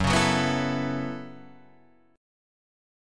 Gbm11 chord